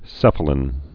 (sĕfə-lĭn) also keph·a·lin (kĕf-)